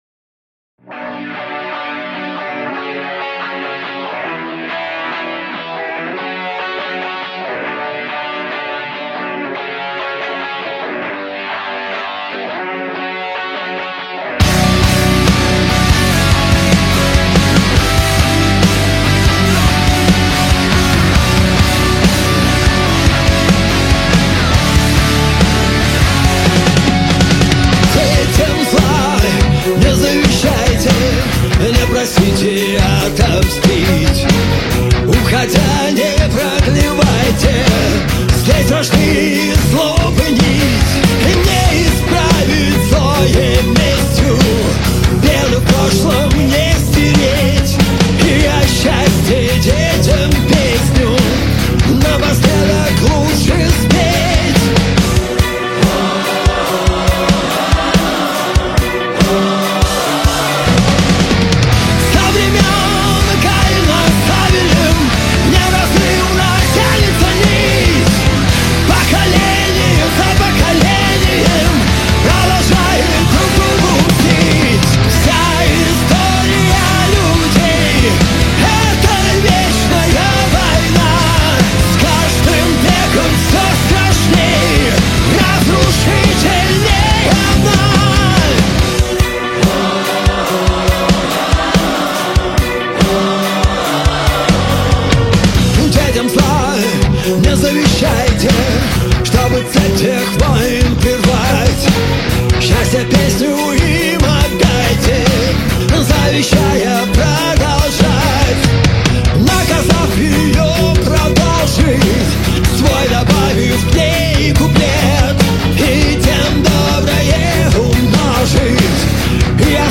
бас-гитара, бэк-вокал
ударные
Песня Рок Жизнь